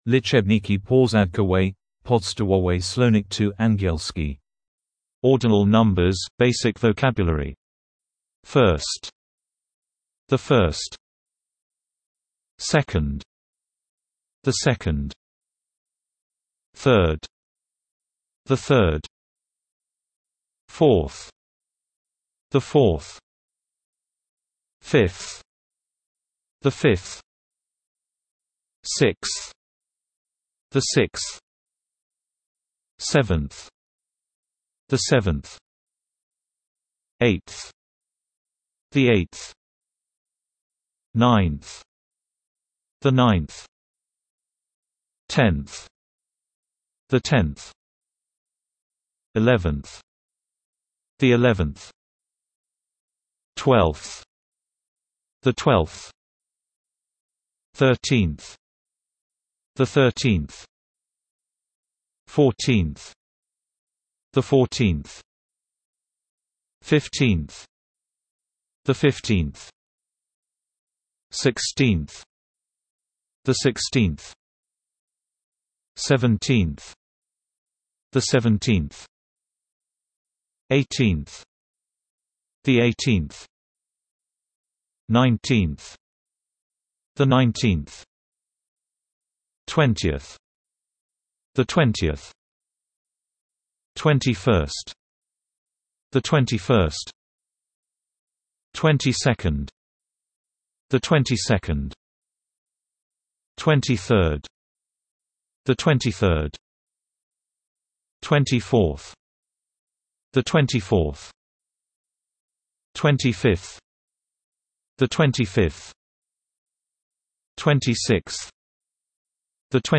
W zestawie znajdują się angielskie liczebniki porządkowe wraz z poprawną wymową lektorską.